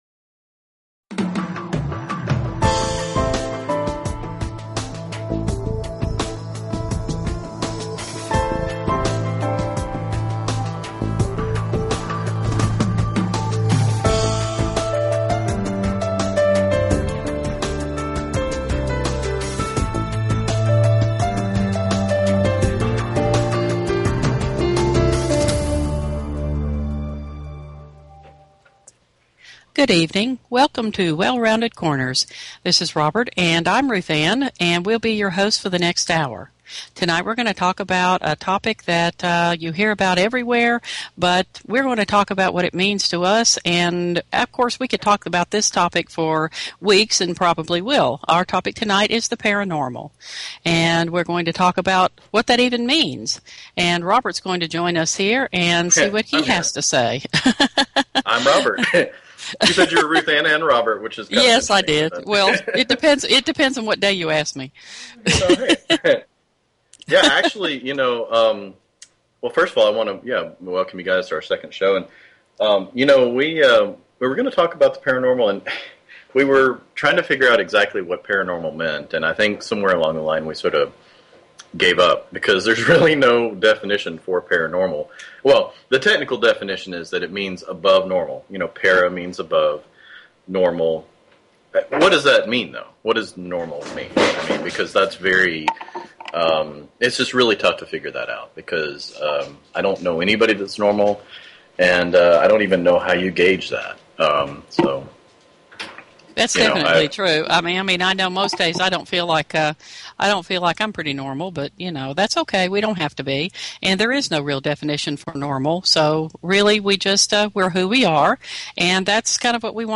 Talk Show Episode, Audio Podcast, Well_Rounded_Corners and Courtesy of BBS Radio on , show guests , about , categorized as
Discussion of EVP's, plus we played one that we recorded!